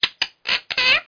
parrot0c.mp3